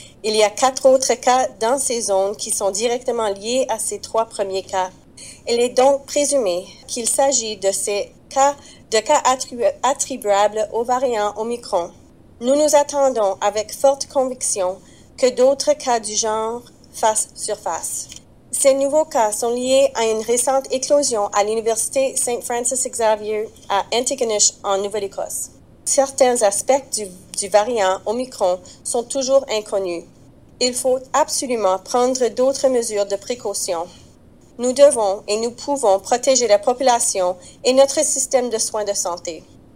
La médecin hygiéniste en chef, la Dre Jennifer Russell en parle davantage lors d'une conférence de presse :